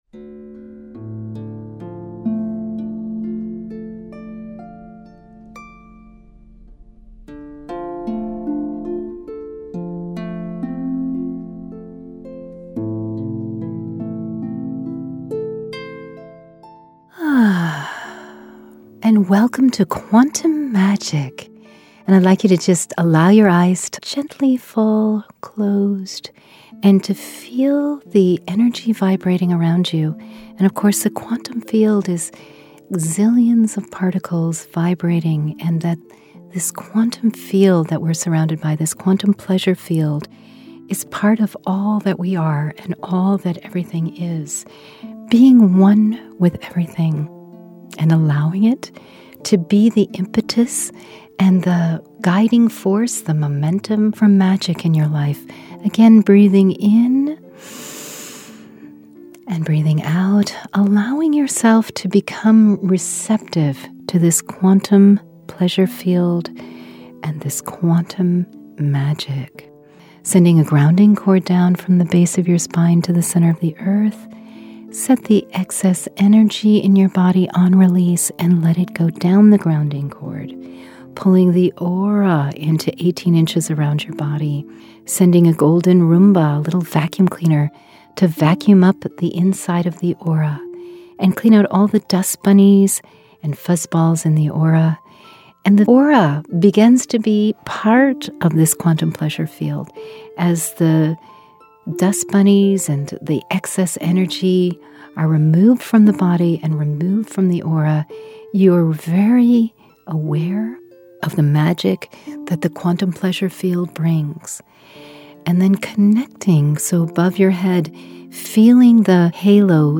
Q5 Meditations